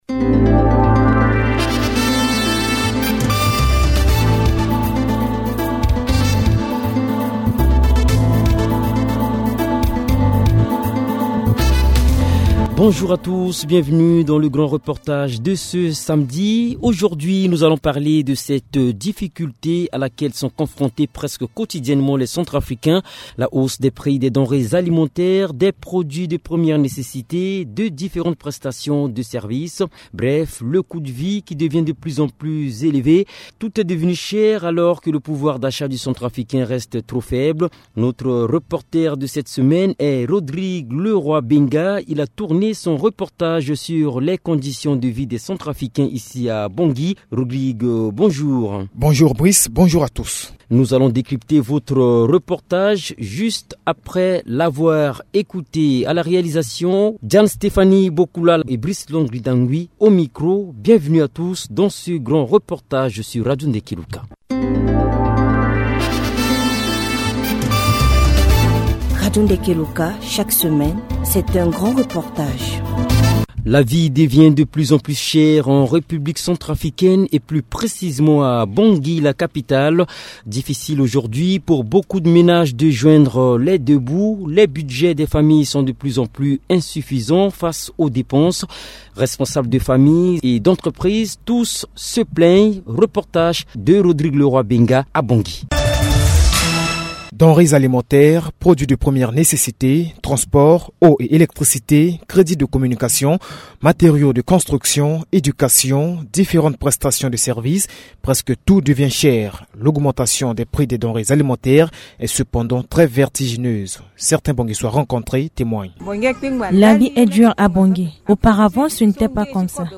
D’abord le pouvoir d’achat du centrafricain resté statique alors que le prix des produits de première nécessité ne cesse de galoper. L’eau, a été au contact de la population qui donne son opinion sur cette question.